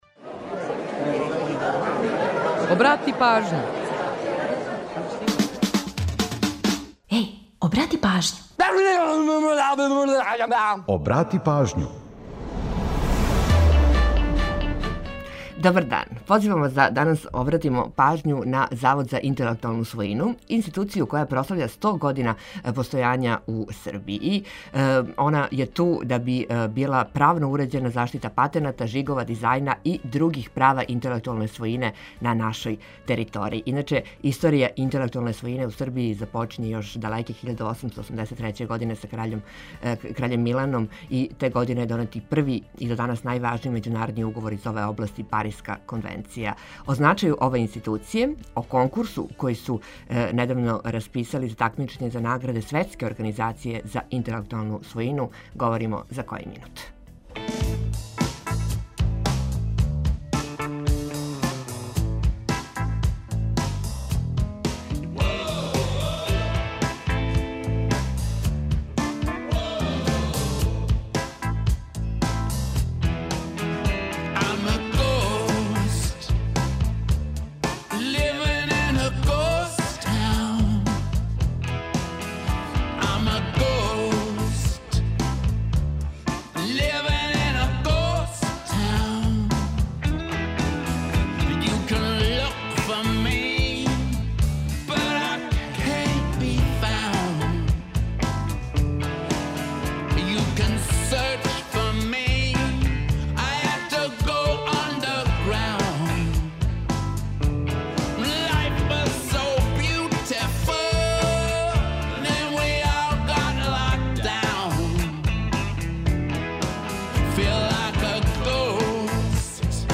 Ту је и пола сата резервисаних за домаћицу, музику из Србије и региона, прича о једној песми и низ актуелних занимљивости и важних информација, попут најаве предстојећег дуплог издања Мартовског фестивала документарног и краткометражног филма.